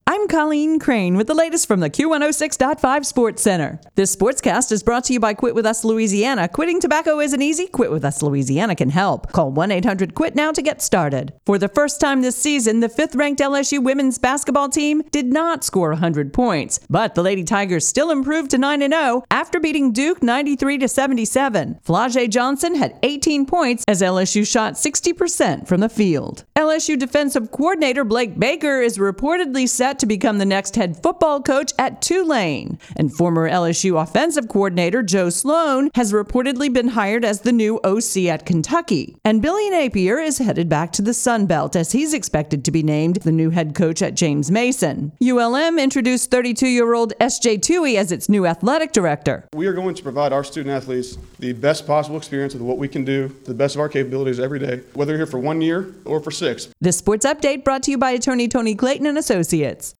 KQKL AM sportscast.mp3